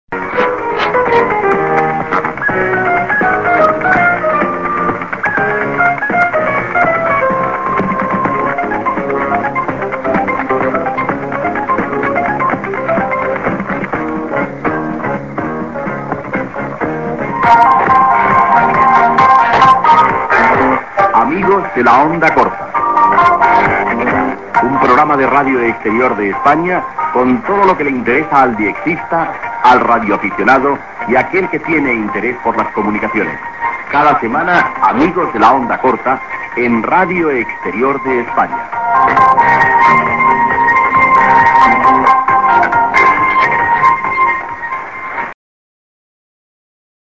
Mid music->ID(man)->music